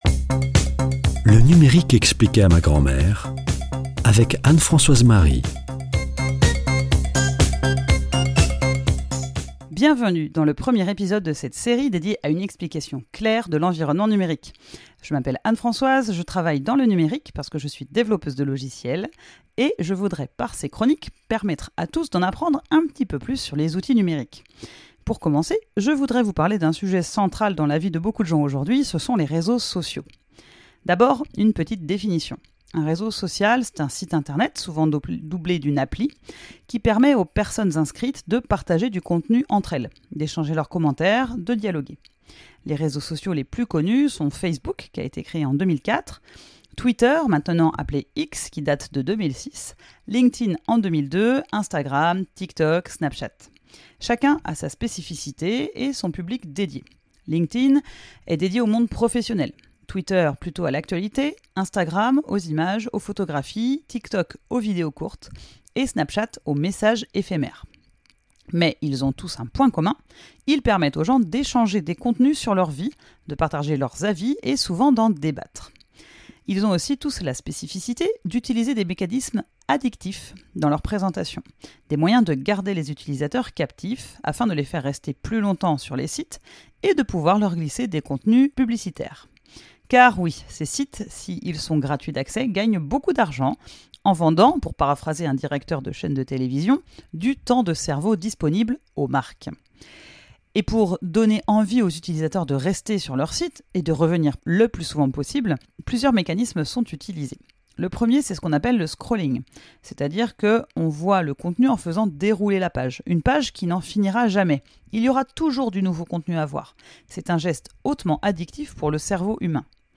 Présentatrice